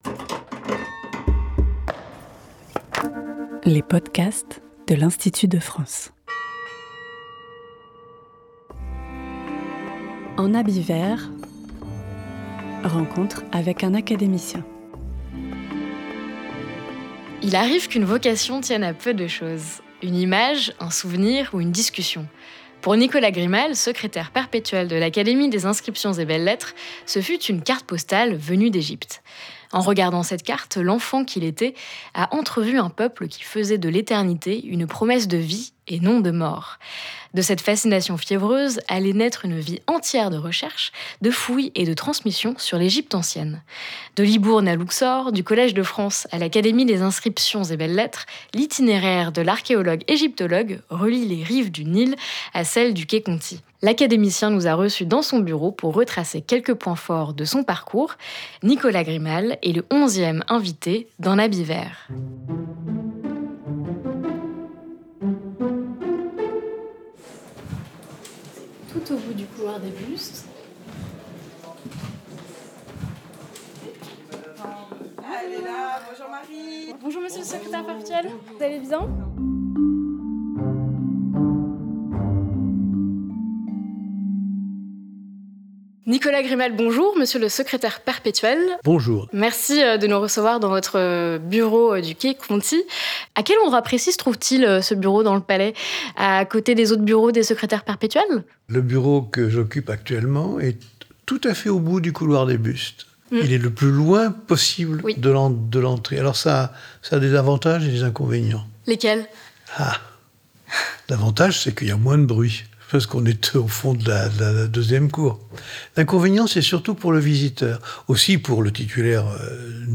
C’est dans son bureau que l’académicien nous a reçus, pour évoquer les grandes étapes d’un itinéraire savant et passionné.